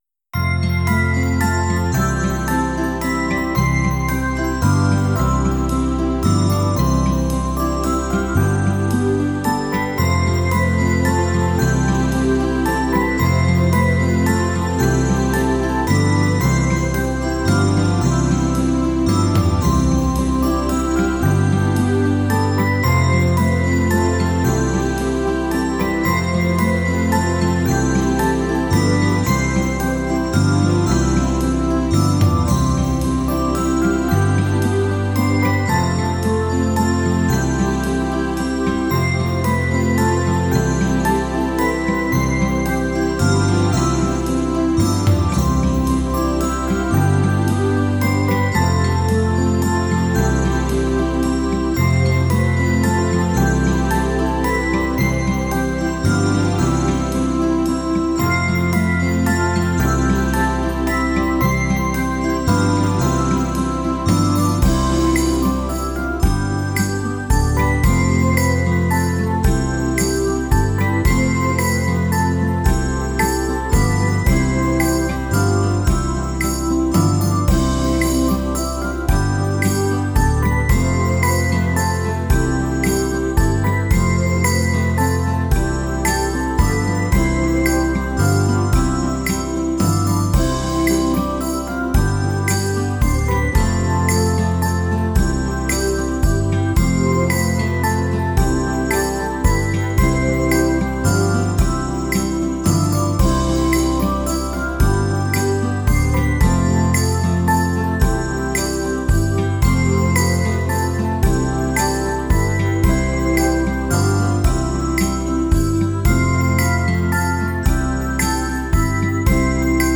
vieux Noël anglais
Karaoké en Do majeur